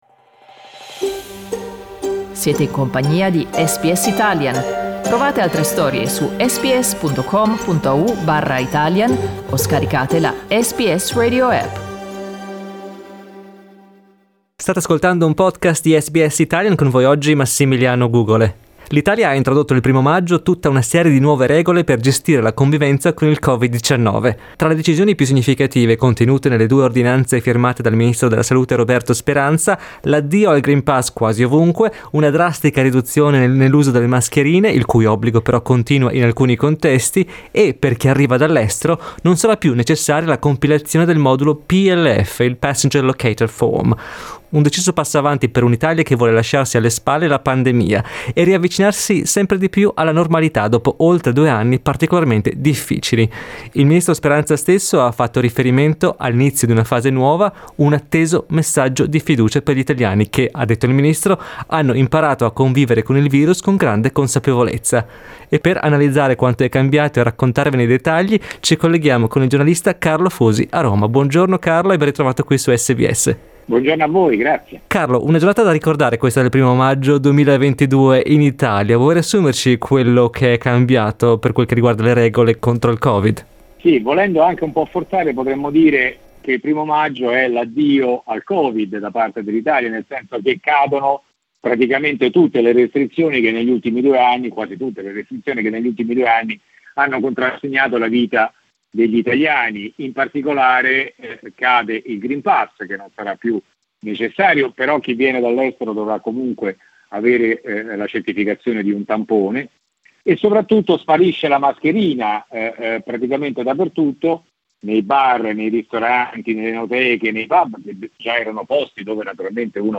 Ascolta l'intervento del giornalista